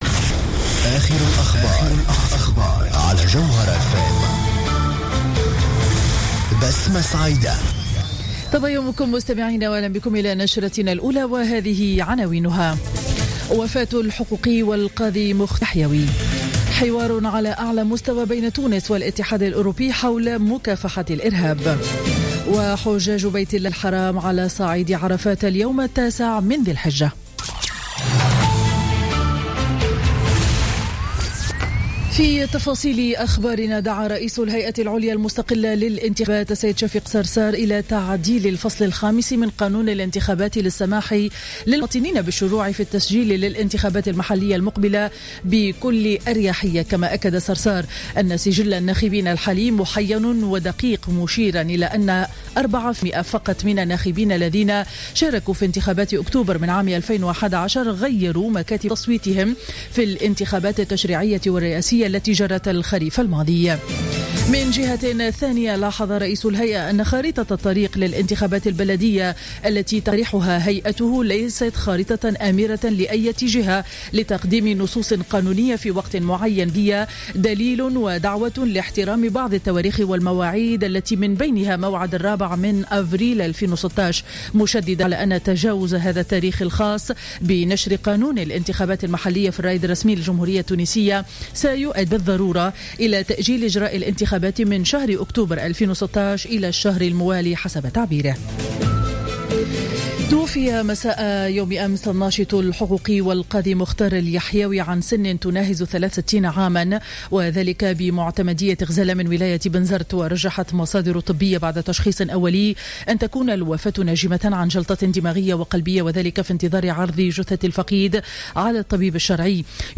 نشرة أخبار السابعة صباحا ليوم الأربعاء 23 سبتمبر 2015